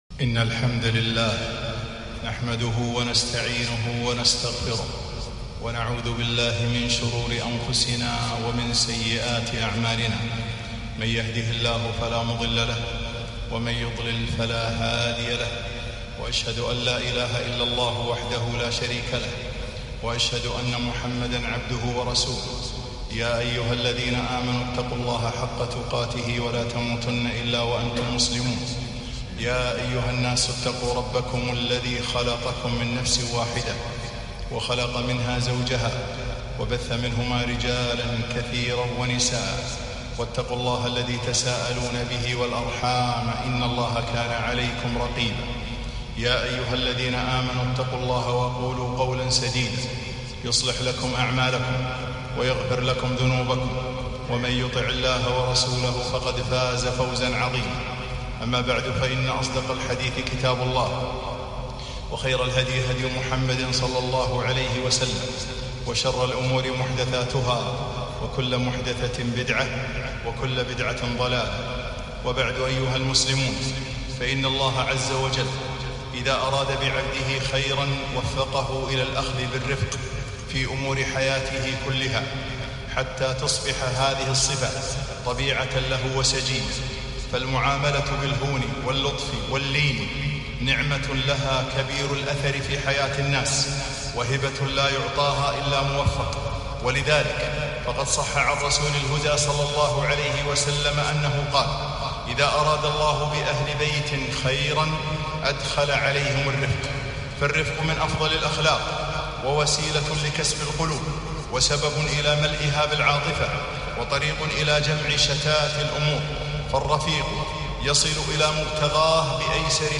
خطبة - الرفق